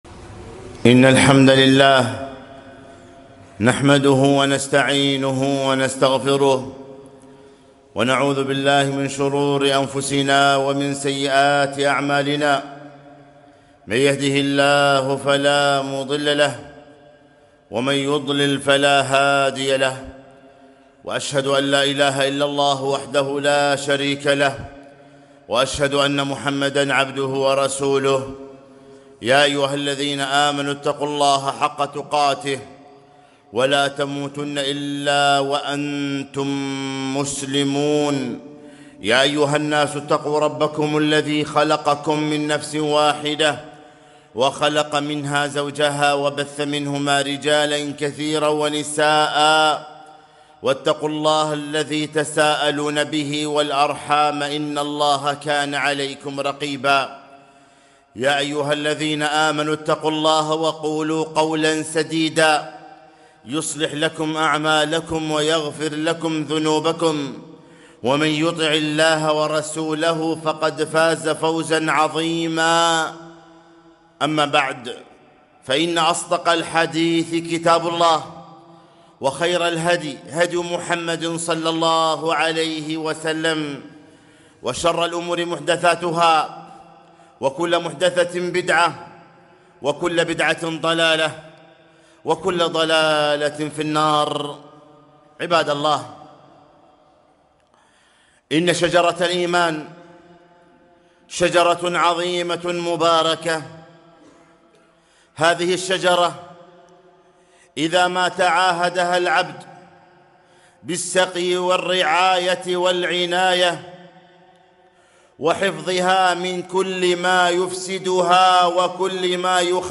خطبة - أثر الإيمان في صلاح الإنسان